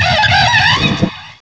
pokeemerald / sound / direct_sound_samples / cries / monferno.aif